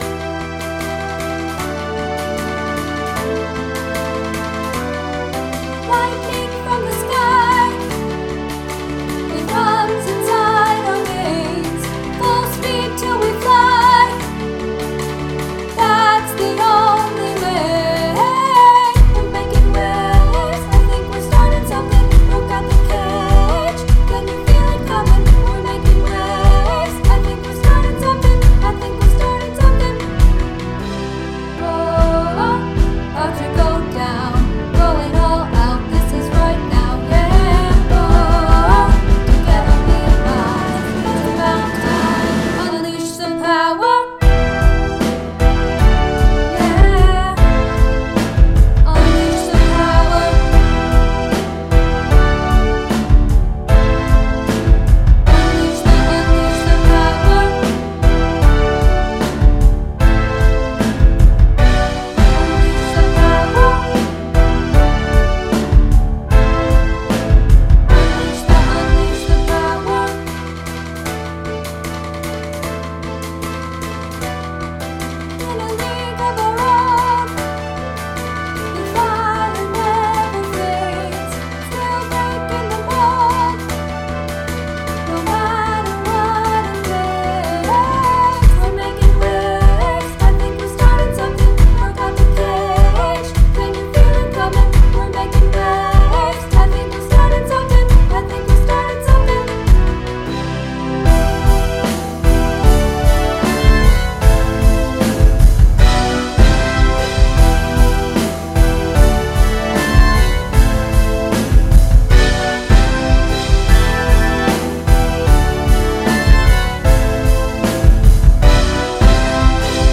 Unleash your rock